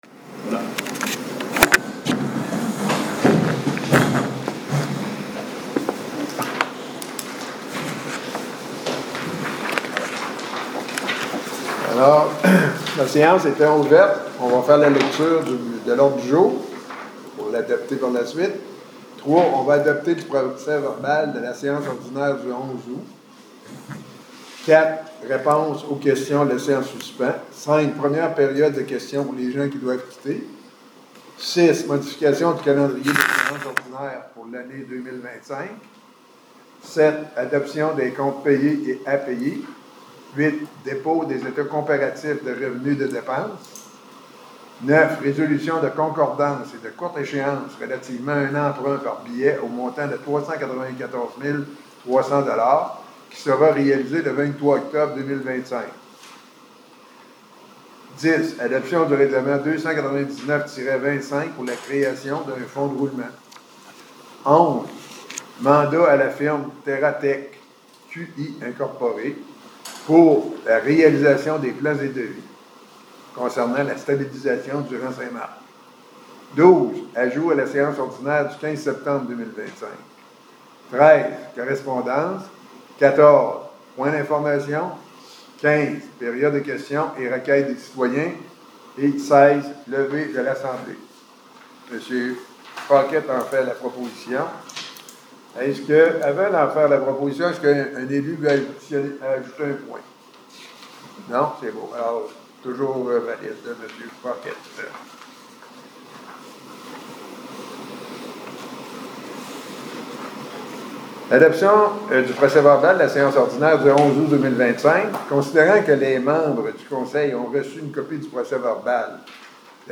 Un problème est survenu avec l'enregistrement vidéo de la séance ordinaire du 15 septembre 2025.
Audio de la séance ordinaire du 15 septembre 2025